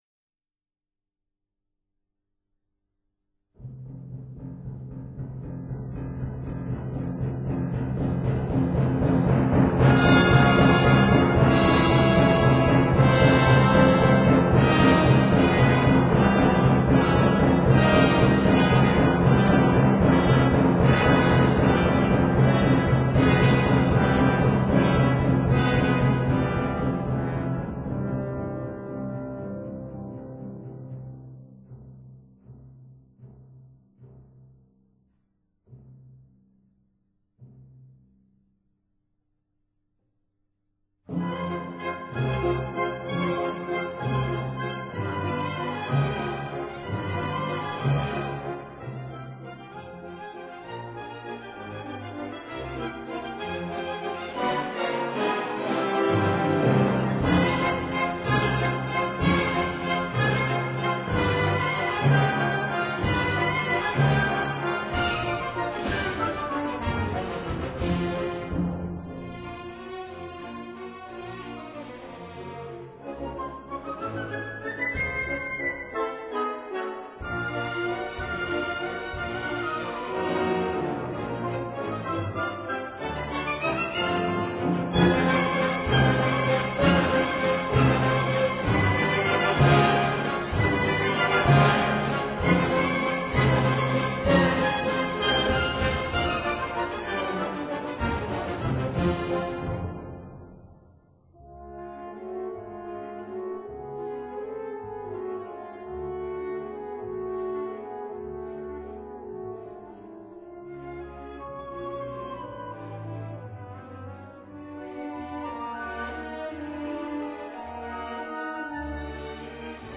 管弦樂
交響詩《在中亞細亞的草原上》(1880)，鮑羅定另一首為人所熟識的管弦樂曲，生動的描繪一個對他奇異而陌生的地區。